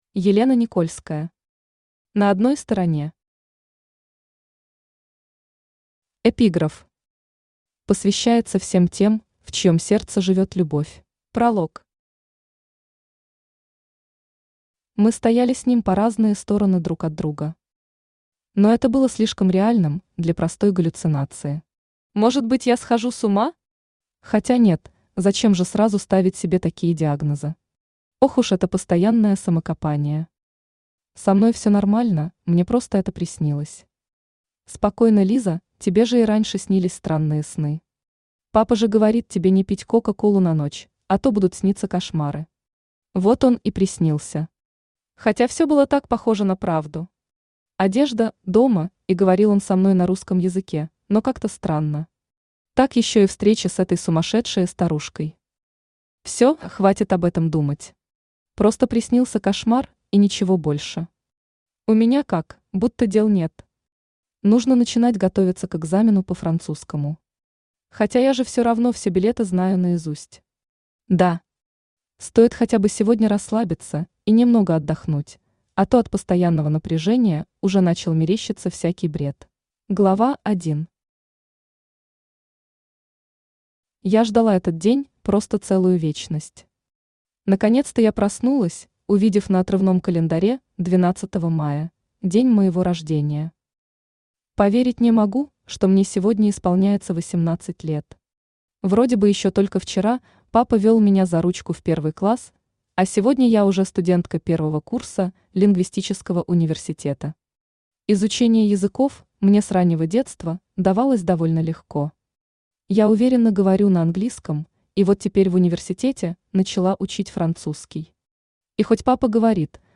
Аудиокнига На одной стороне | Библиотека аудиокниг
Aудиокнига На одной стороне Автор Елена Никольская Читает аудиокнигу Авточтец ЛитРес.